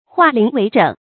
化零為整 注音： ㄏㄨㄚˋ ㄌㄧㄥˊ ㄨㄟˊ ㄓㄥˇ 讀音讀法： 意思解釋： 把零散的部分集中為一個整體。